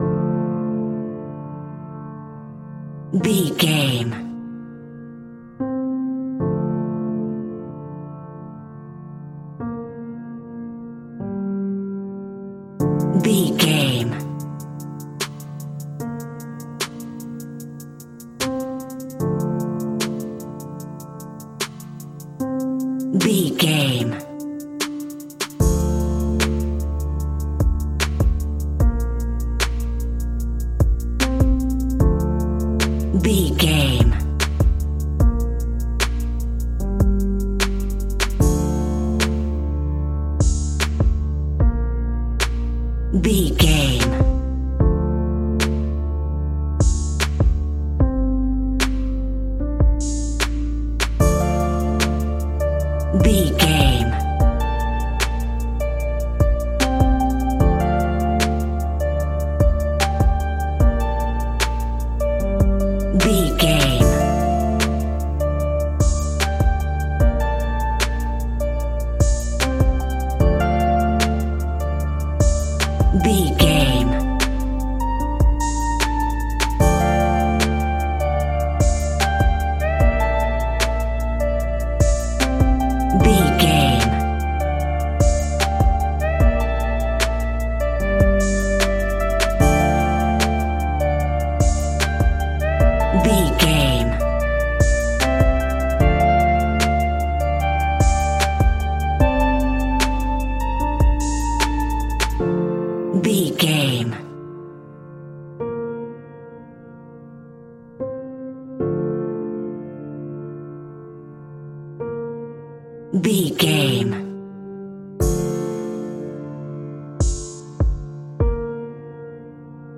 Aeolian/Minor
Slow
light
relaxed
tranquil
synthesiser
drum machine